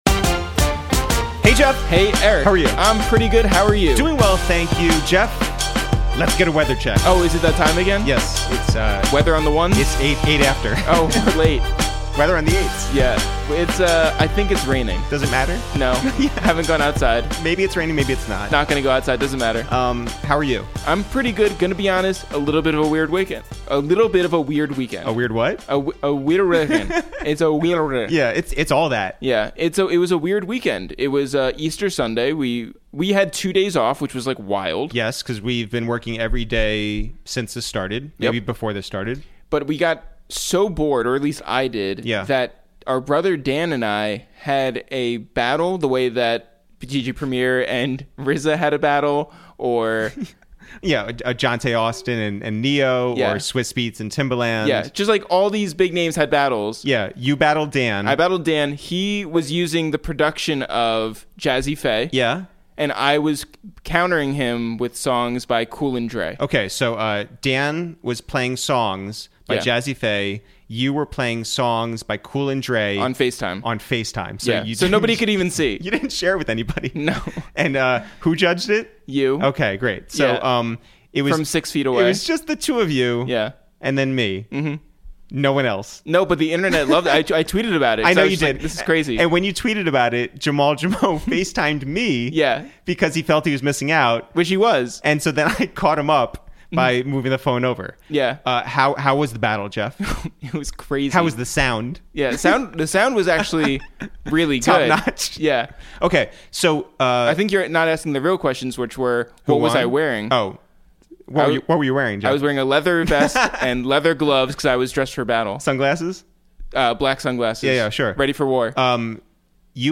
Today on Episode 26 of Quarantine Radio, we make calls from our Upper West Side apartment to check in on Brooklyn's own Fabolous, who talks about why he was predicted to be the first to use Coronavirus in a rhyme, getting traded from Atlantic to Def Jam for Musiq Soulchild, an...